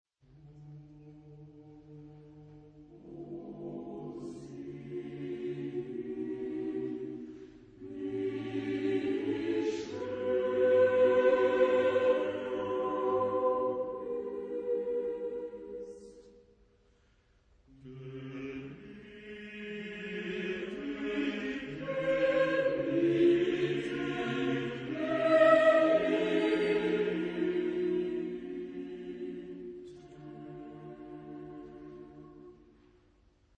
Epoque: 20th century  (1970-1979)
Genre-Style-Form: Motet ; Cycle ; Secular
Type of Choir: SSAATTBB  (8 mixed voices )
Soloist(s): Sopran (1) / Tenor (1)  (2 soloist(s))
Tonality: free tonality